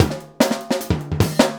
LOOP39--03-R.wav